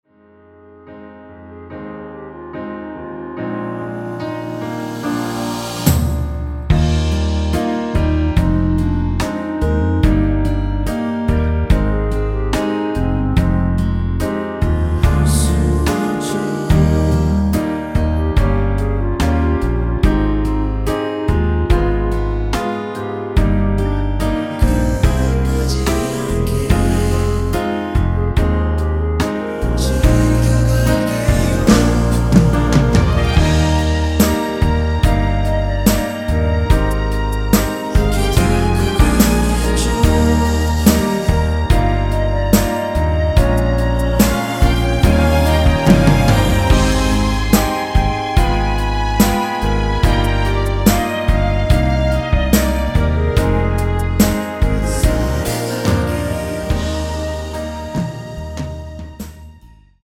원키 멜로디와 코러스 포함된 MR입니다.(미리듣기 확인)
Eb
앞부분30초, 뒷부분30초씩 편집해서 올려 드리고 있습니다.
중간에 음이 끈어지고 다시 나오는 이유는